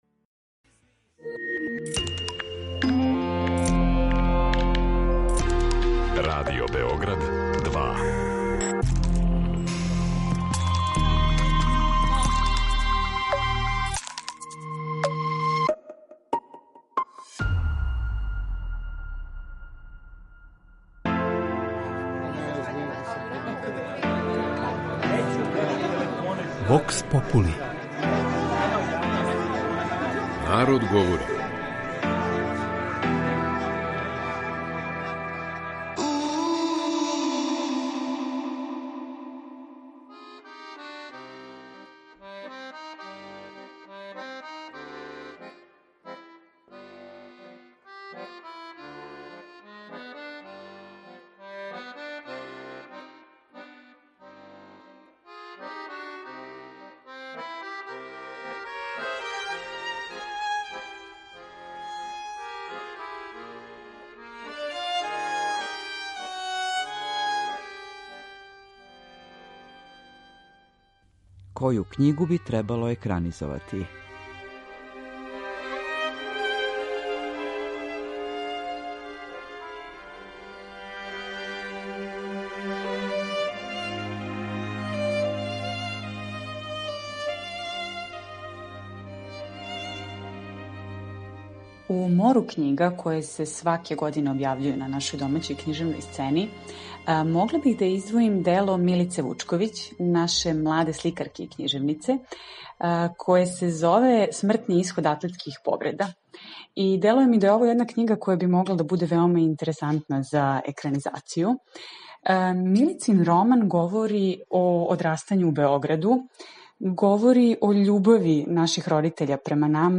У данашњој емисији питали смо наше суграђане коју књигу би волели да виде у њеном екранизованом облику.